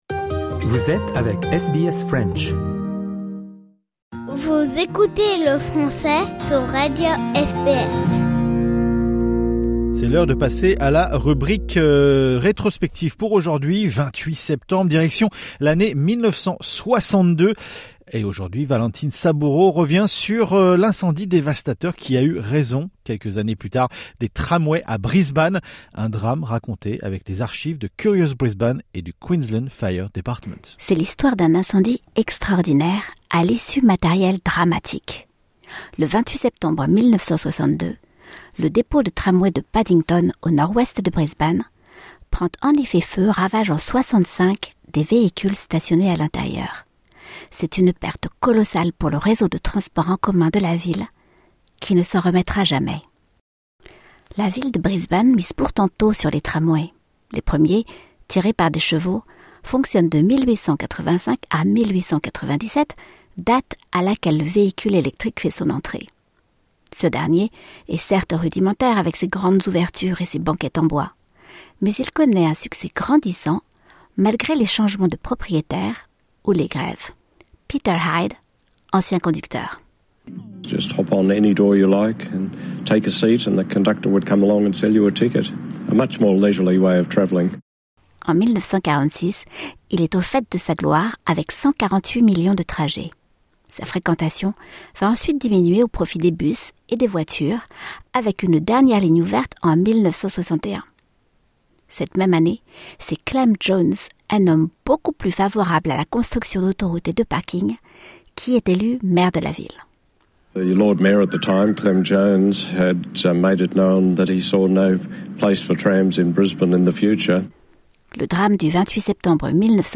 Un drame raconté avec les archives de Curious Brisbane et du Queensland fire department.